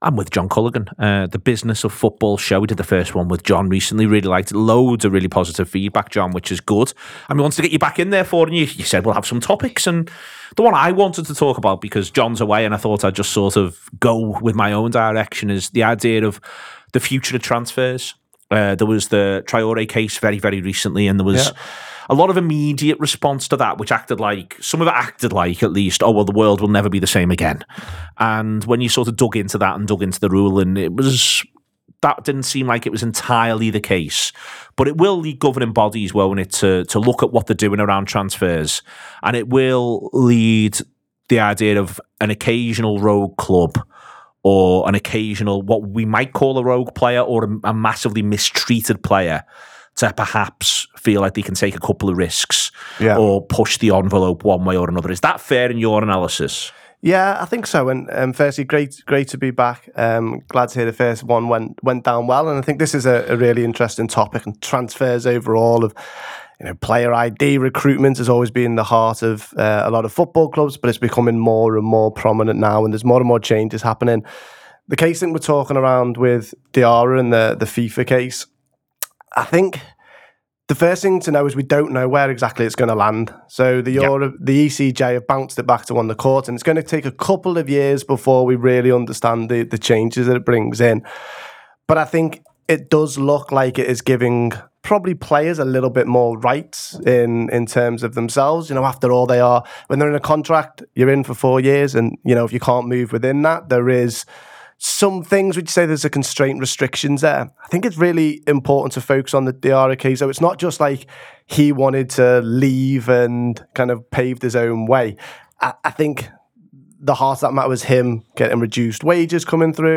is a discussion about the CJEU’s (court of justice of the European Union) ruling against FIFA’s transfer rules.